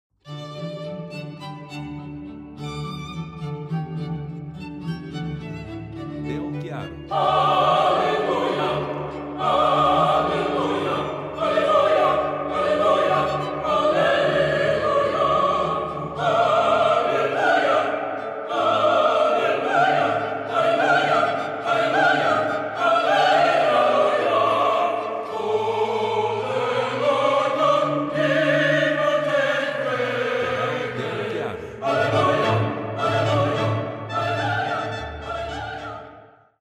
músicas para casamentos